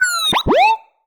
happy3.ogg